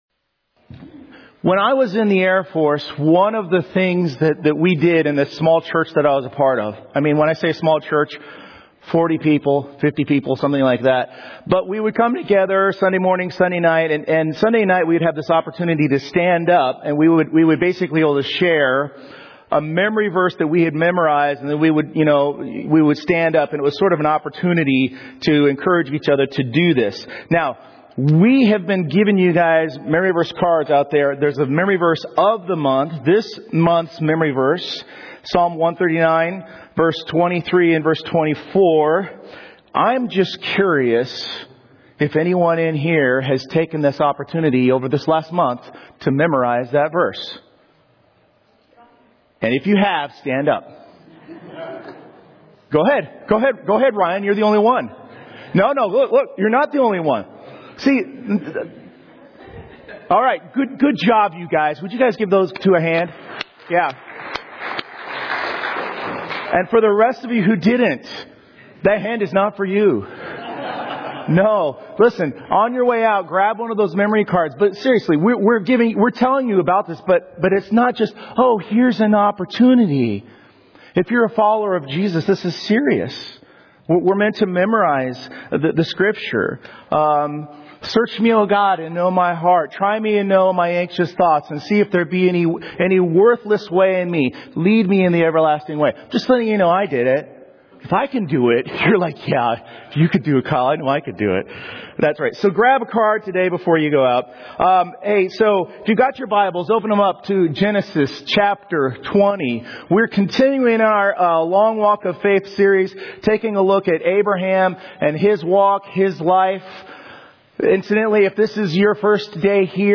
2026 Sermons